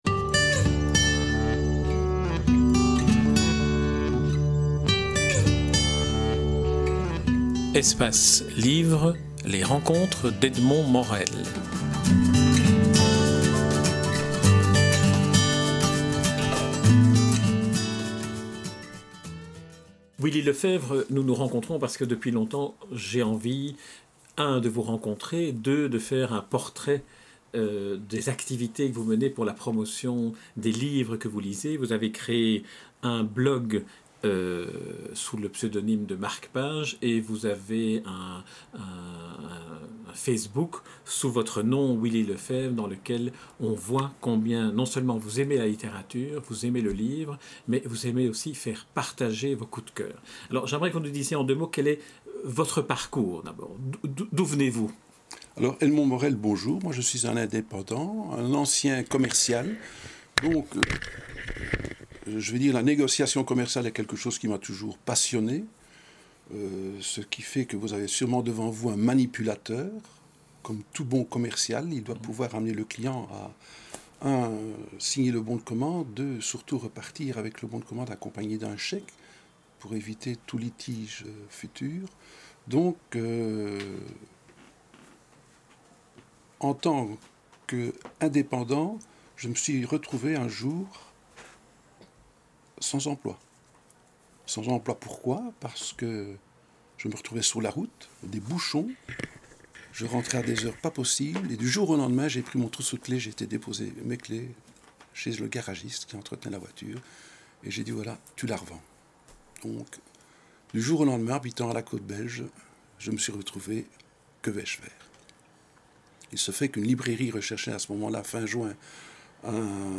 Nous l�avons rencontr� chez lui, � Bruxelles. Les murs de son appartement sont tapiss�s de livres, lus, relus, � lire...